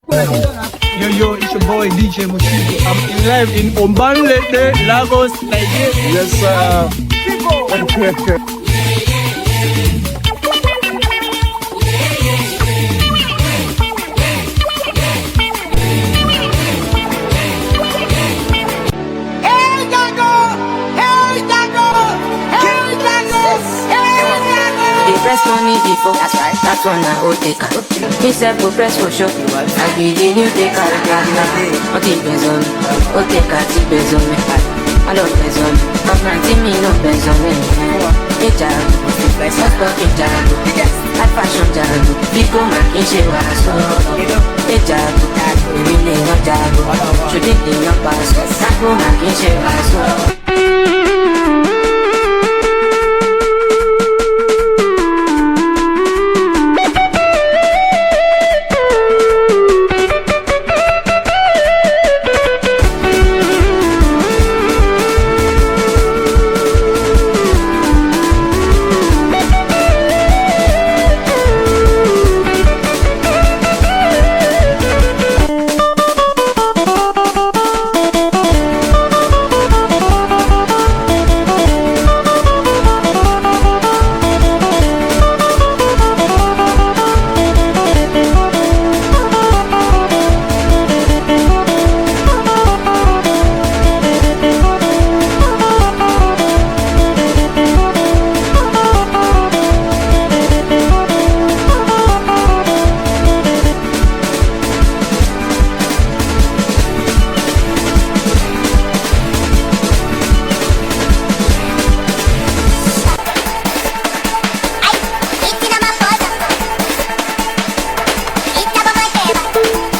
street-flavored vocals